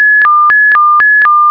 1 channel
KLAXONWAVE.mp3